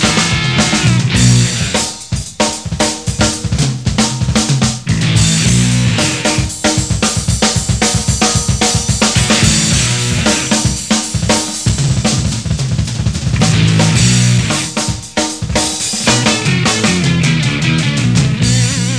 three piece band
vocals
drums